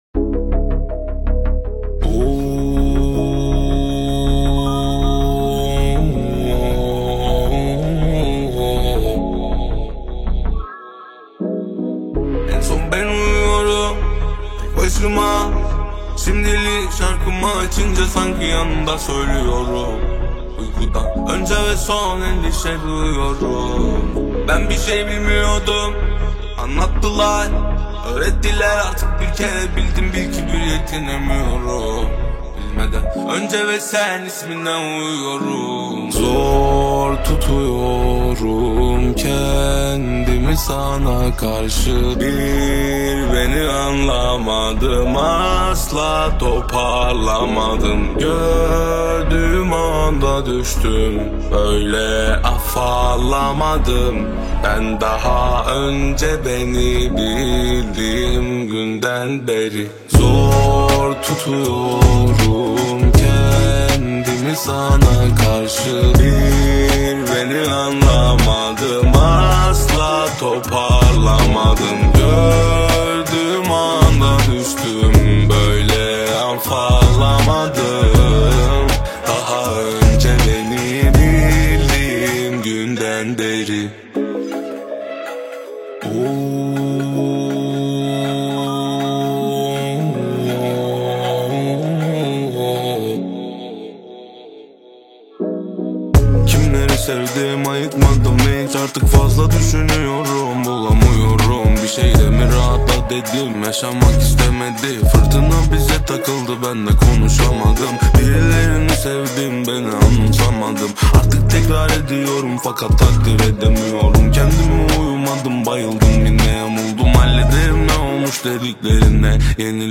Турецкая песня